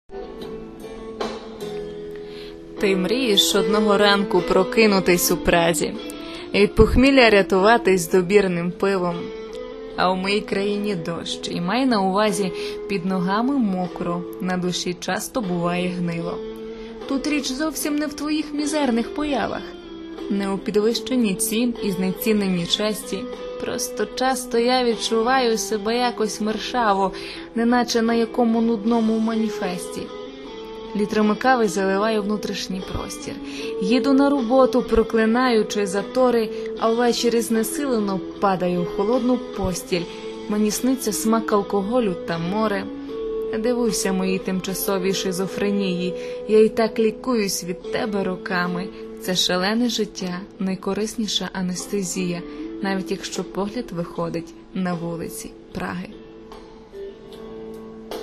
не знаю, чи так задумано, але у голосі прослуховувалася певна іронія apple give_rose
справді, так було задумано, щоб голос трішки передав настрій вірша...